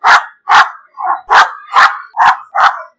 一只小狗的低音